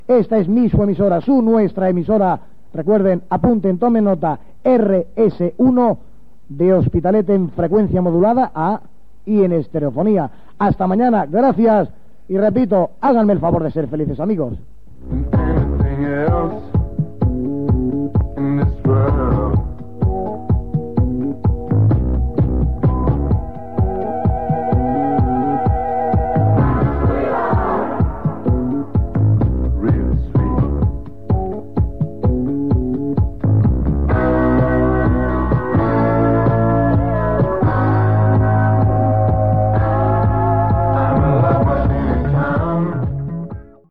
b9f0ae43007ec9a0b86cd50743f1b2efa2b60c31.mp3 Títol RS1 Emissora RS1 Titularitat Tercer sector Tercer sector Comercial Descripció Identificació i comiat.